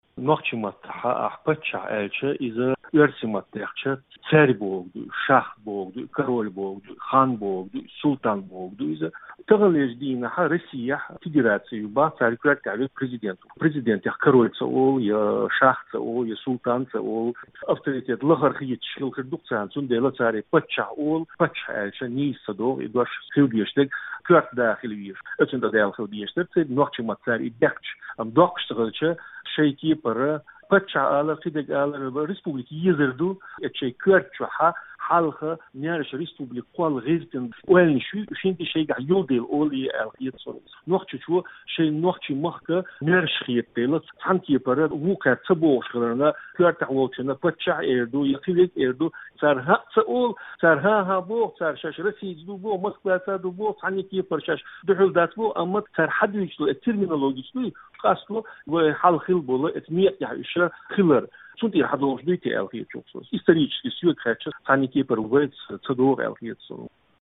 Нохчийчуьра зуда: Дела орцах ца валахь, тхан кхин дан хIума дац